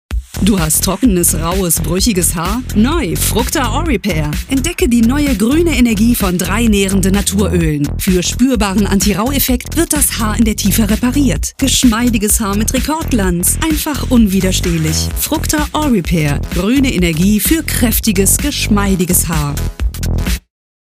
Profi-Stimme, mittleres Alter, Stimmlage Alt, Imagefilme, Werbespots, Hörbücher, Reportagen, Hörspiele, Sachtexte, PC-Spiele, E-Learning, Podcasts, Zeichentrick, Dialekte, Dokumentationen, Synchronisation, Features, Telefonansagen, Hotlines, Kölsch, slawischer Akzent, Mini-Studio, Formate .wav, .mp3
Sprechprobe: Werbung (Muttersprache):
female, middle-aged voice over talent